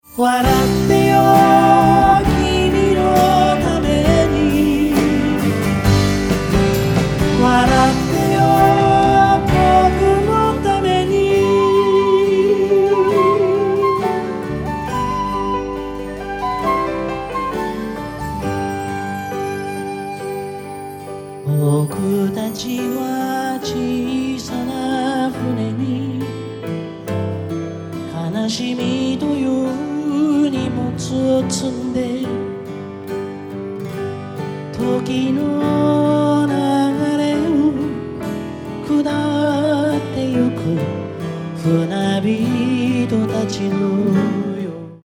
ライブアルバム
2013年7月17・18日　日本武道館にて収録